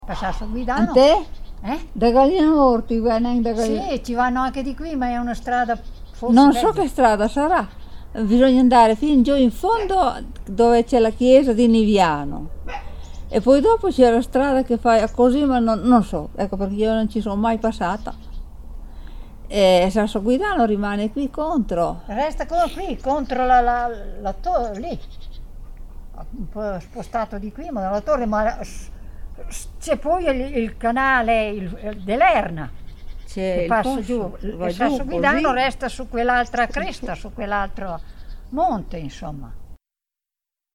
Proponiamo ai bambini l'ascolto di uno spezzone di conversazione registrata su una strada dell'Appennino modenese1, in prossimità di Niviano. Nel brano si può udire la risposta di due signore anziane ad una richiesta di informazione stradale.
…erano due vecchiette: avevano la voce da vecchie